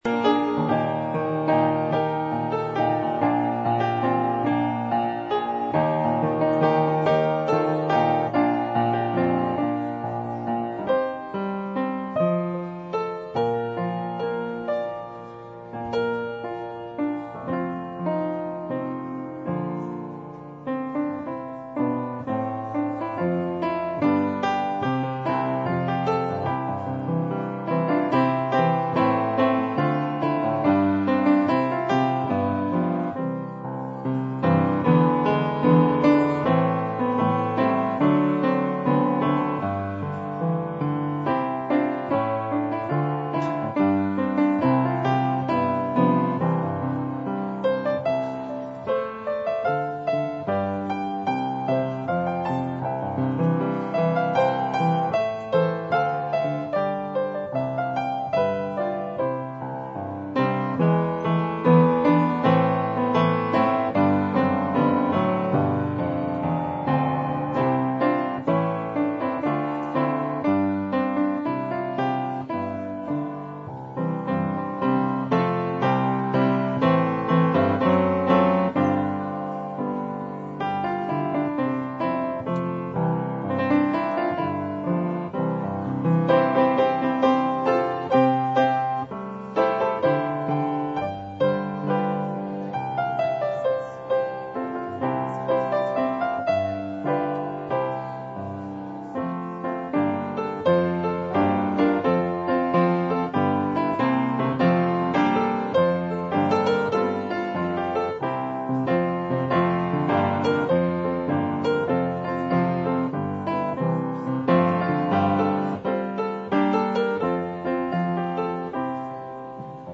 Bible Text: Luke 2:1-20 | Preacher